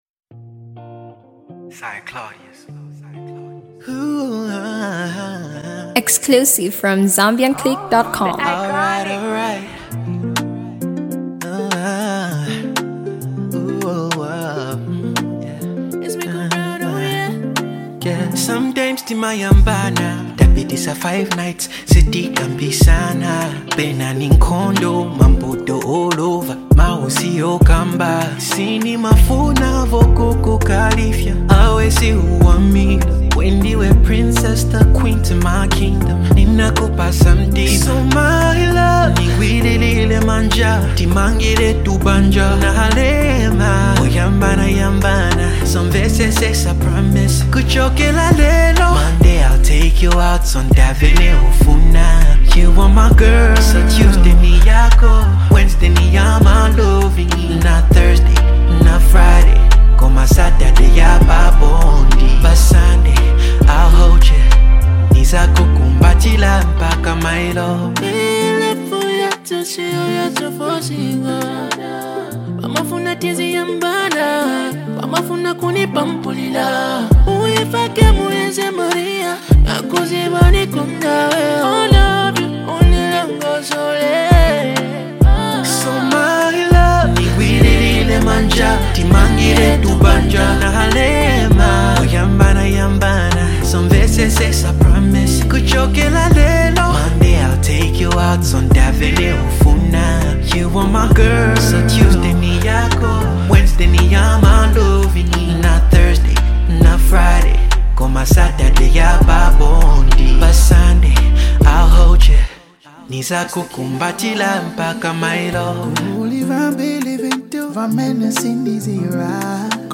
love song
the King of RnB